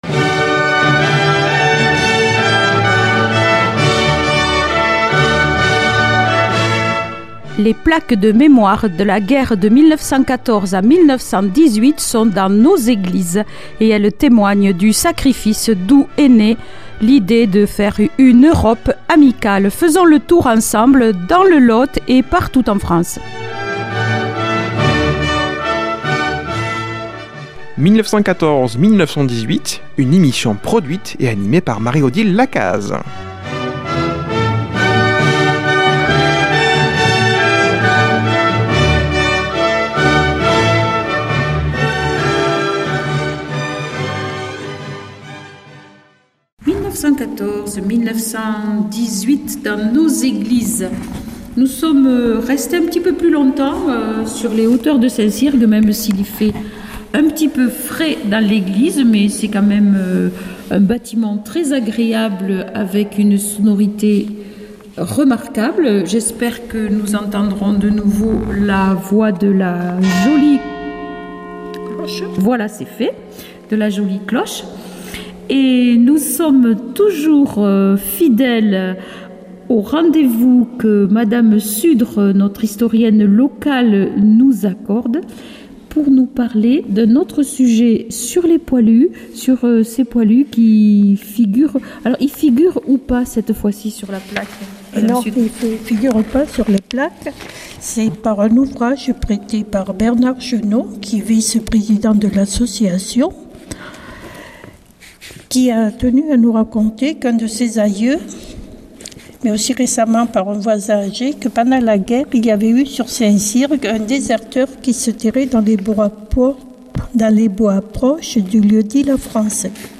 14 18 dans nos églises